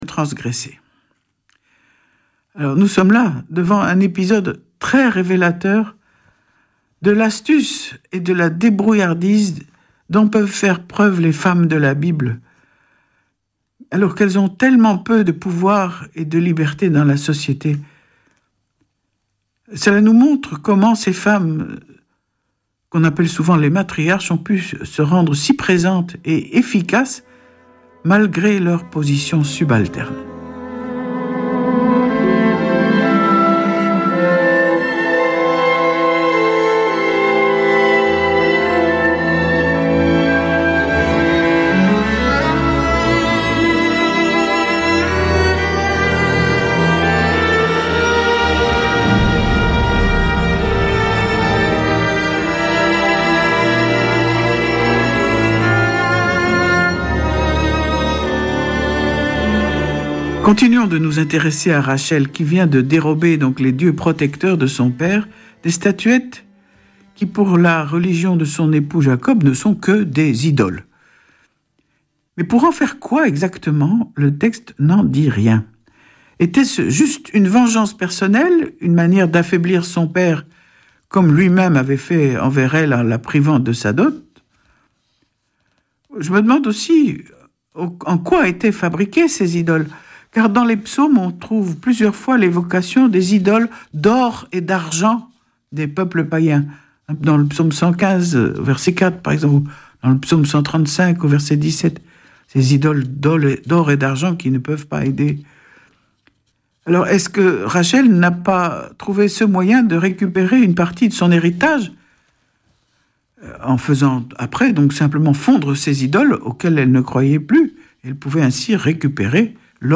Vêpres de Saint Sernin du 20 juil.
Une émission présentée par Schola Saint Sernin Chanteurs